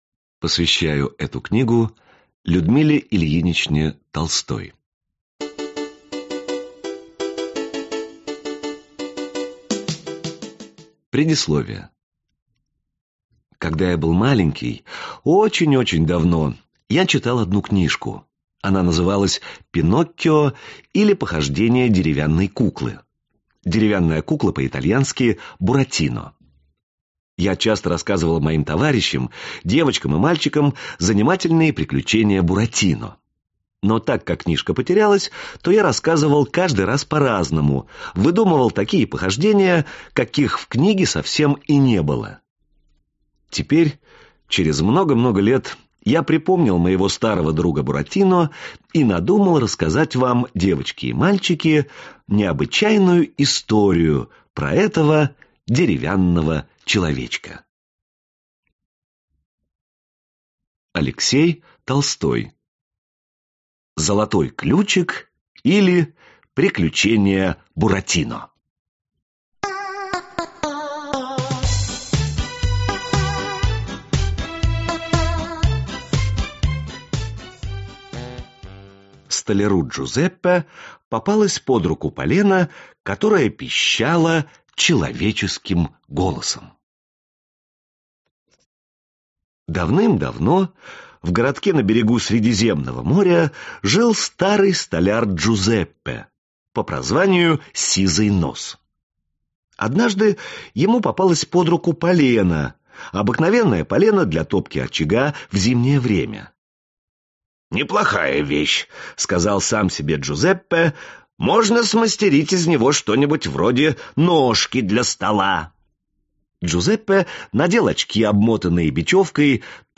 Аудиокнига Золотой ключик или приключения Буратино | Библиотека аудиокниг